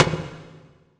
hop.wav